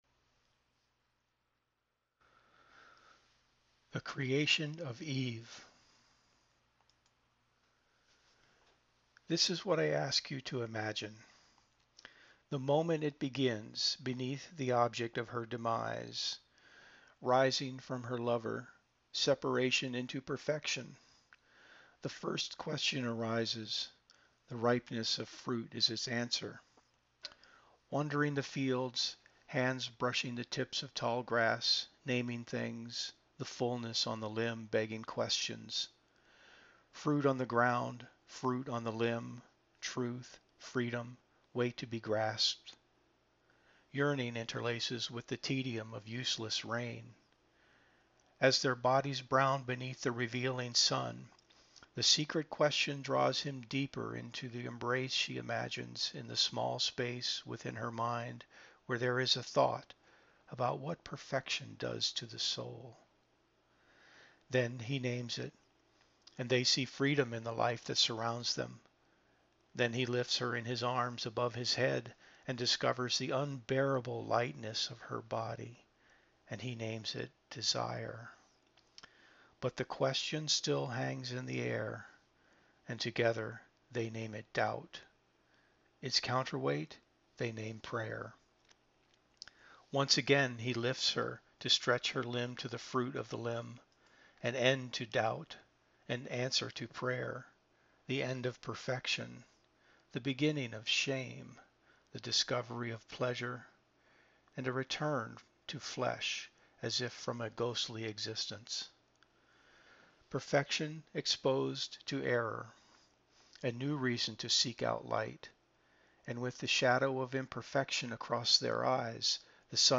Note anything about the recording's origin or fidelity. Open link night at the Bar